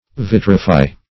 Vitrify \Vit"ri*fy\, v. t.